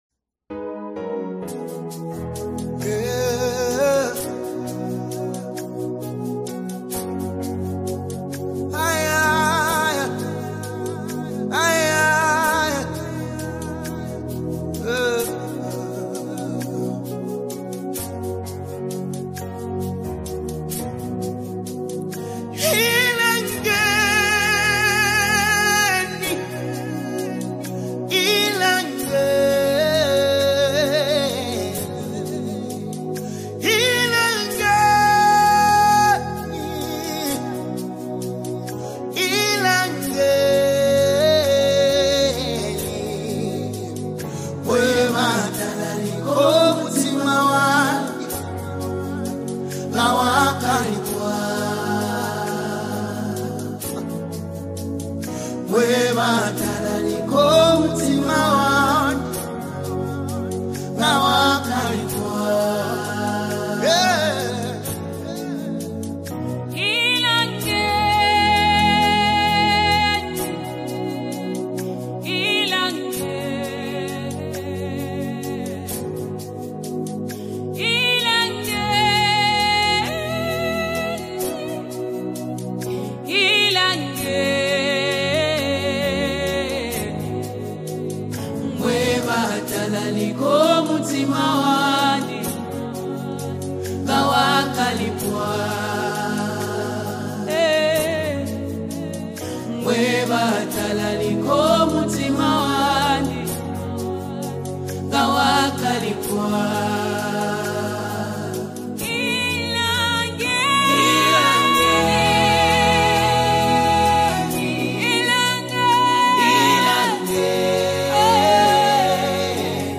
2025 ZAMBIA GOSPEL MUSIC